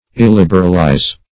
Search Result for " illiberalize" : The Collaborative International Dictionary of English v.0.48: Illiberalize \Il*lib"er*al*ize\, v. t. [imp.